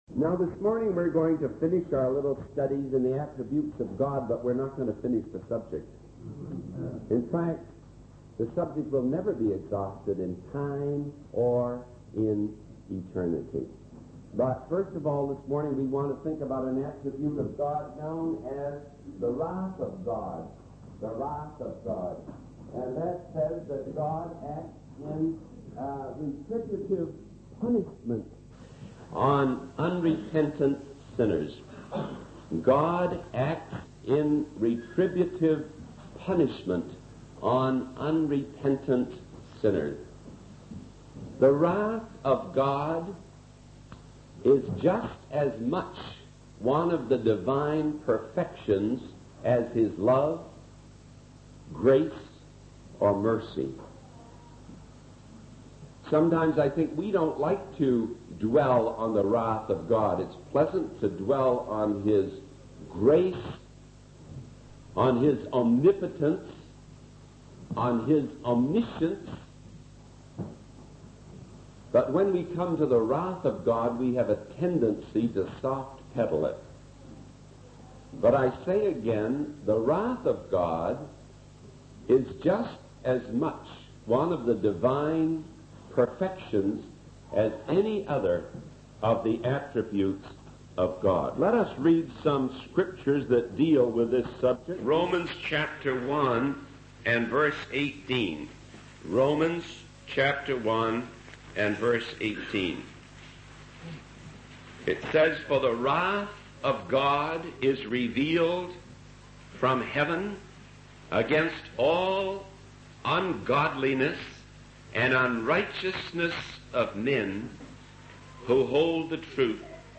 In this sermon, the speaker reflects on the finite nature of human life and the infinite nature of God. They emphasize the privilege of serving God and encourage young people to dedicate their lives to Him.